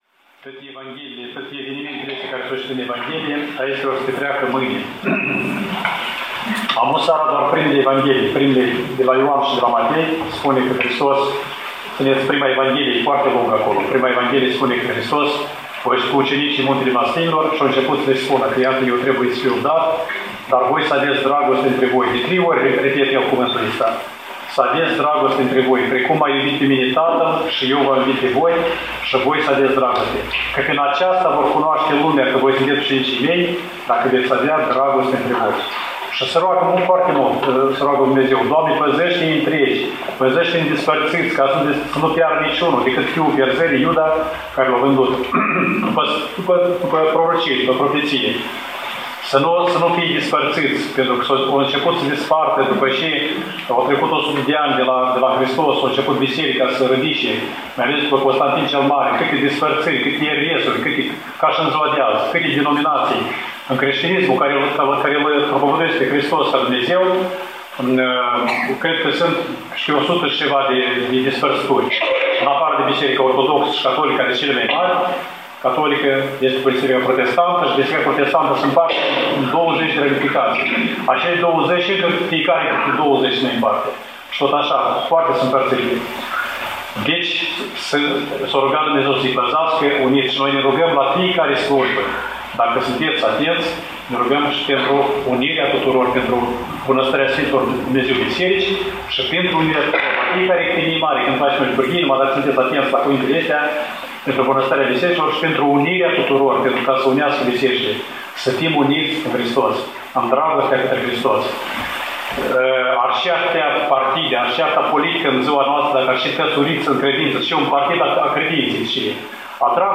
06:30 - Sfânta Liturghie (predica părintelui)
18:00 - Citirea celor 12 Evanghelii (predica părintelui)